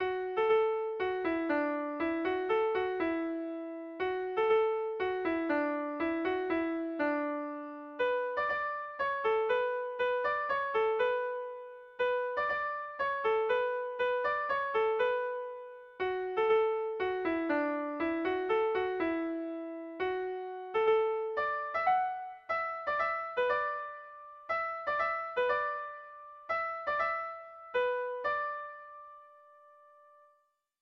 Gabonetakoa
A1A2BBAD